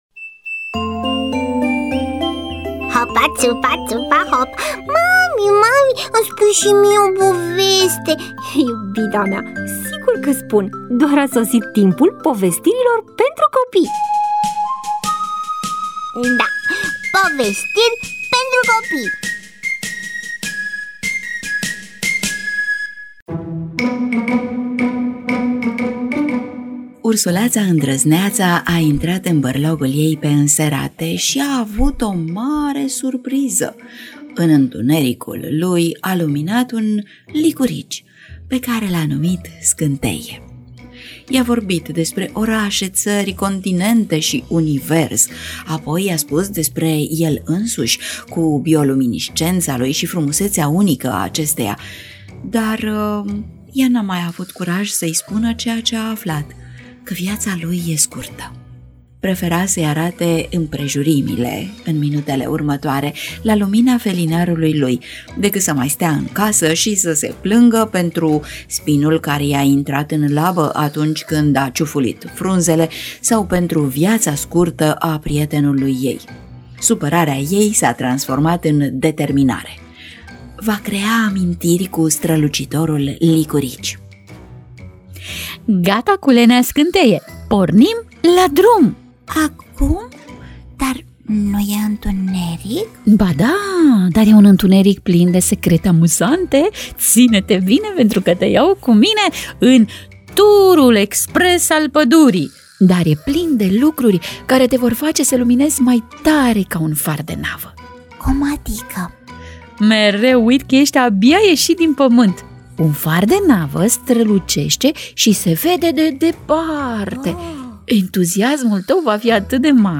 EMISIUNEA: Povestiri pentru copii